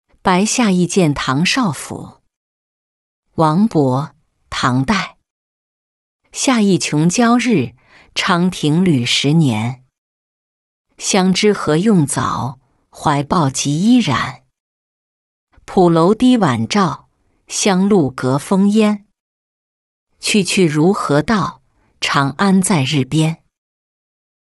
白下驿饯唐少府-音频朗读